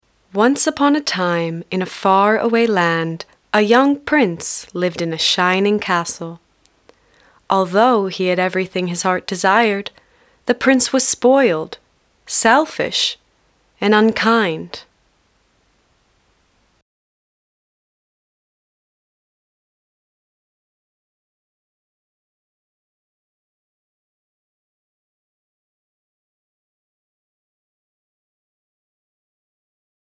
Bandes-son
Beauty and the Beat Audiobook
- Basse
American-Standard (native)